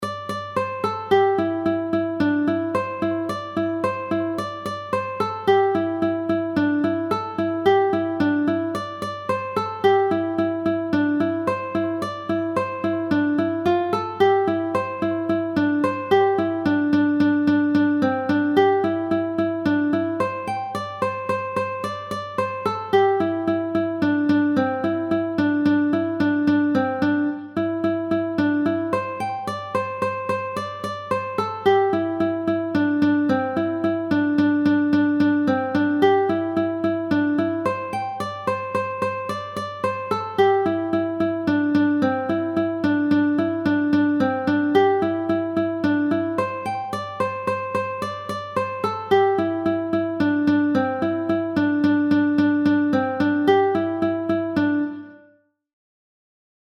Mélodies